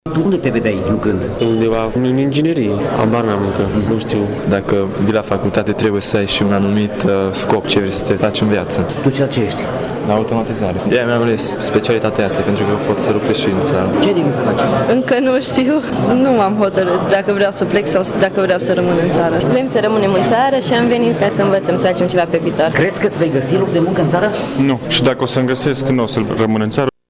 Unii studenți de la Informatică și Inginerie nu sunt deciși ce vor face după absolvire, alții știu sigur că vor pleca din țară: